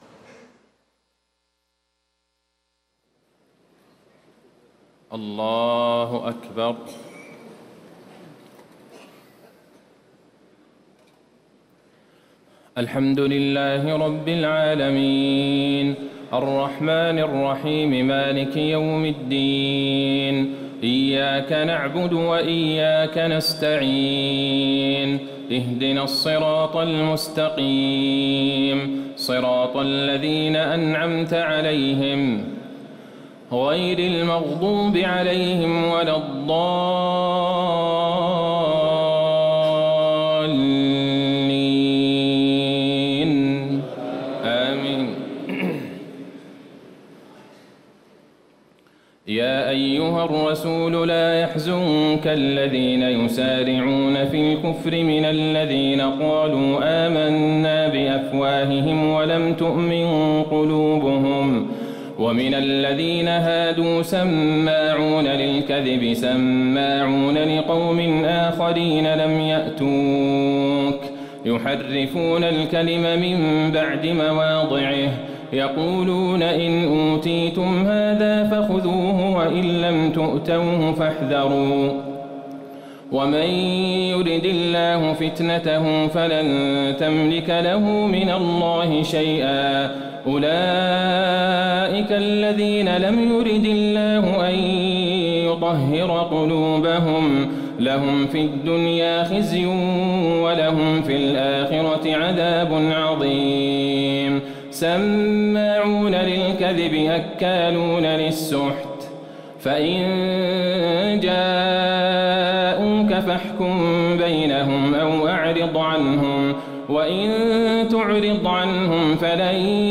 تهجد ليلة 26 رمضان 1438هـ من سورة المائدة (41-81) Tahajjud 26 st night Ramadan 1438H from Surah AlMa'idah > تراويح الحرم النبوي عام 1438 🕌 > التراويح - تلاوات الحرمين